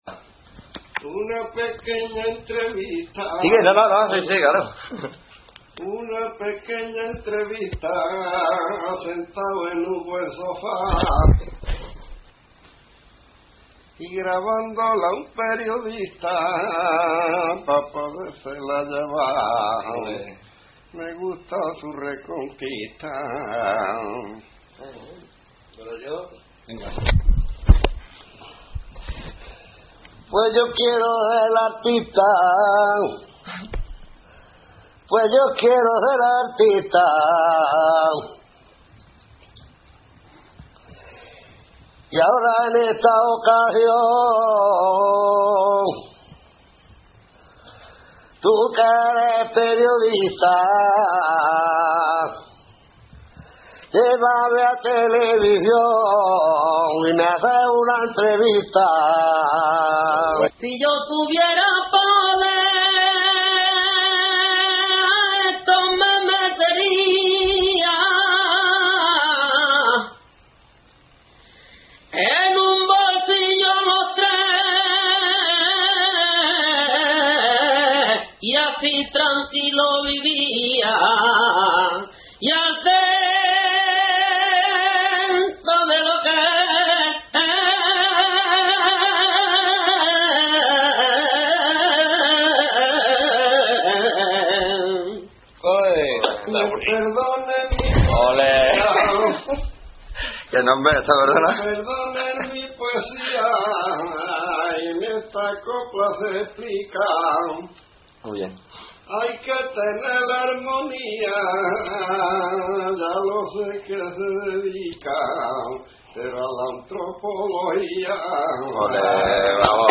Título: Trovos de Huétor-Tájar [Grabación sonora]
Entrevista grupal a los tres troveros FLV; Video Cámara Digital Grabado en directo en casa de los intérpretes el 19 de abril de 2007 Sumario: las temáticas tratadas en esta sesión de trovos son de carácter ad hoc, característica básica del trovo: Presentación, coplillas, mano a mano y despedida